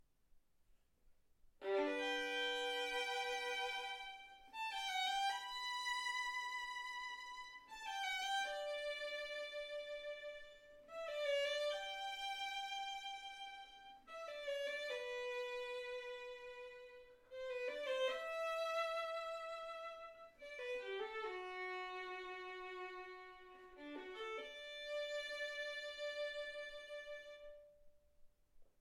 Hegedű etűdök Kategóriák Klasszikus zene Felvétel hossza 00:29 Felvétel dátuma 2025. december 8.